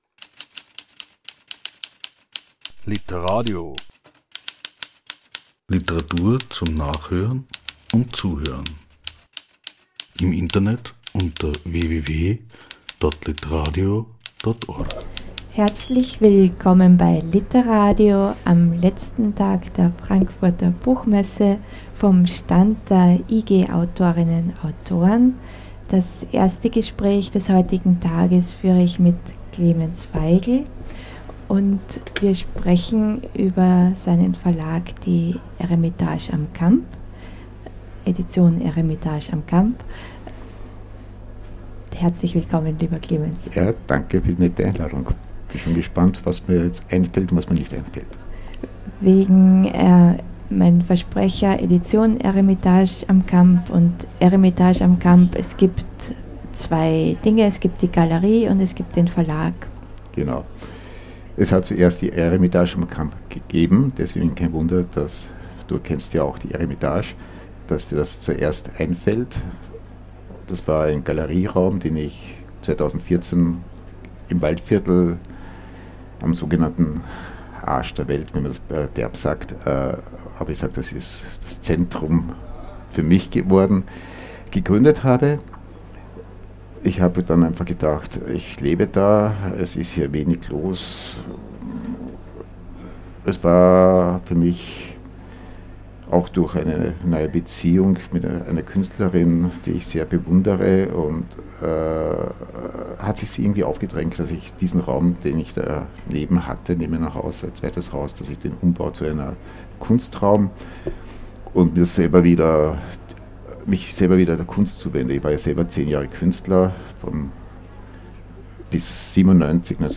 Der Verleger im Gespräch.